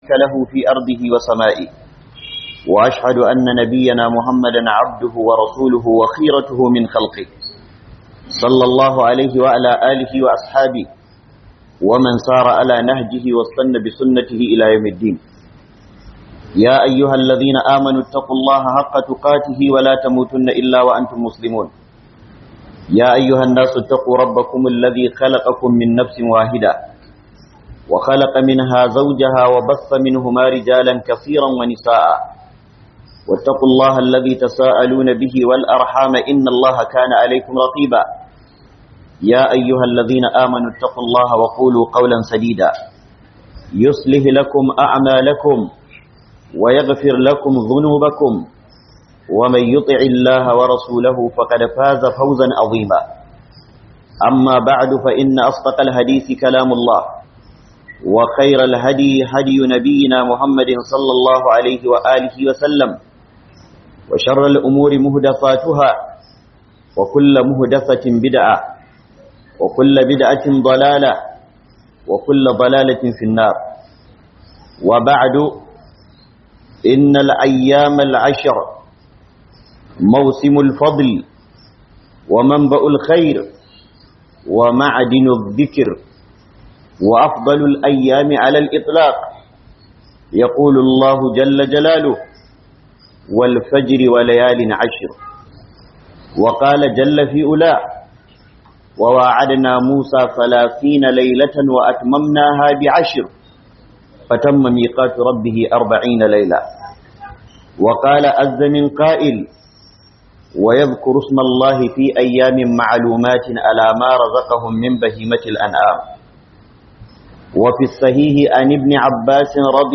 KWANAKI 10 MASU FALALA NA FARKON ZHULHIJJA - Huduba